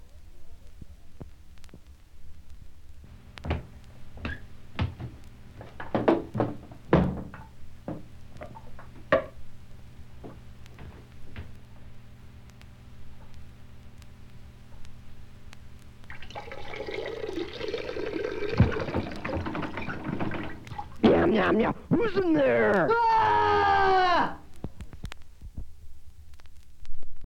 "Who's In There?", a brief skit in which a piss break is interrupted by an attempt to open a door and an angry, pro-wrestler sounding voice asking, �Who�s In There?� (actually, it sounds more like �Meow, meow, meow, who�s in there?� but unless the cat was potty trained I don�t think the piss track sounds quite right for that).